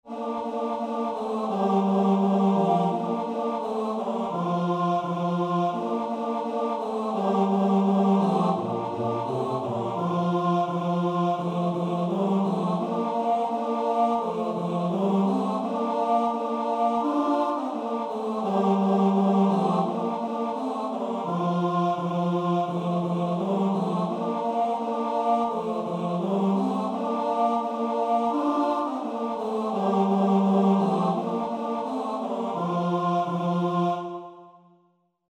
– Комп'ютерне відтворення нот: mp3.